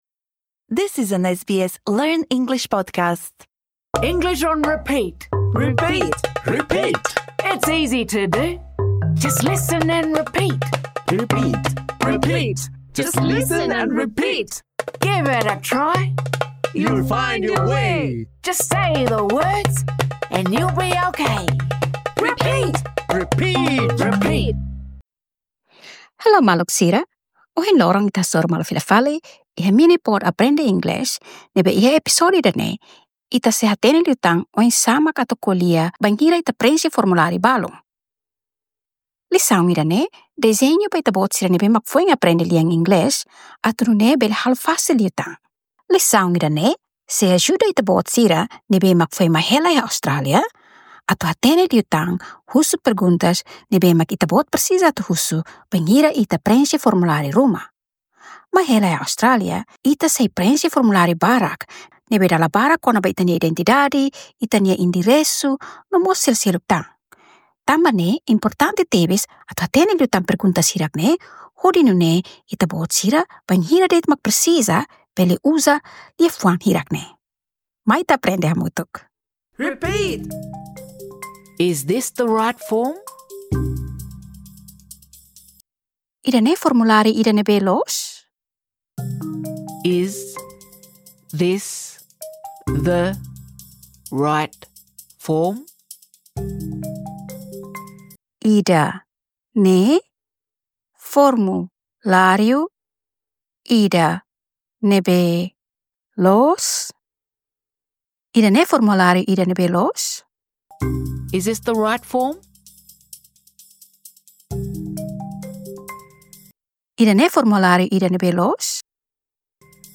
Lisaun ida-ne'e dezeña ba ita-boot sira ne'ebé foin aprende lian inglés ne'ebe fasil. iha epizódiu ida-ne'e, ita sei pratika hatete liafuan sira tuirmai: Is this the right form?